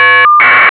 Generate a Caller ID burst (Bell 202 MDMF).